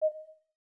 Holographic UI Sounds 29.wav